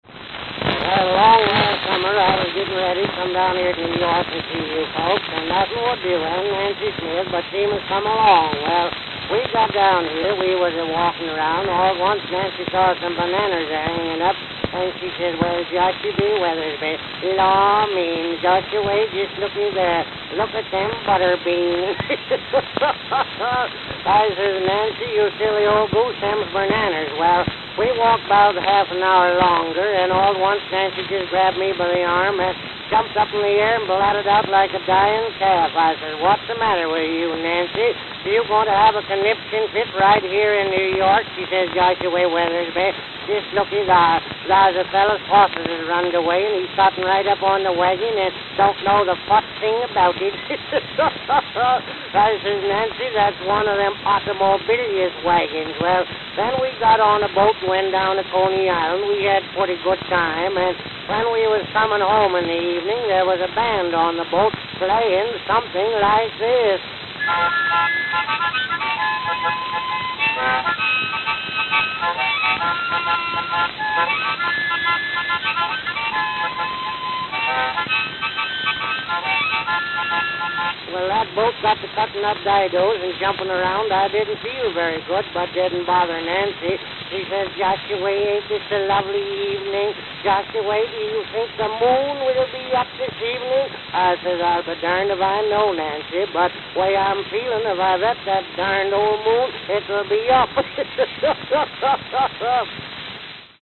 New York, New York
Note: Played at 77 RPM. Very worn, especially at start.